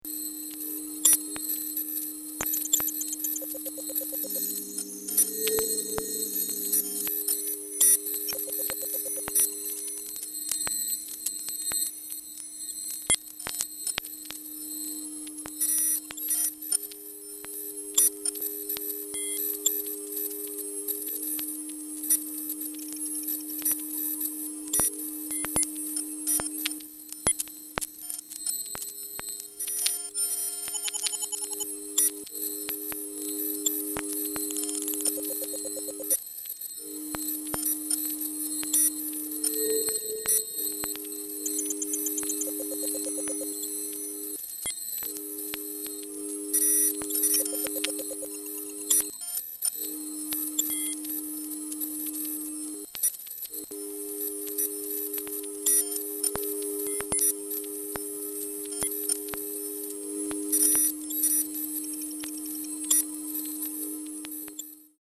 sound art
Soundscapes
Noise music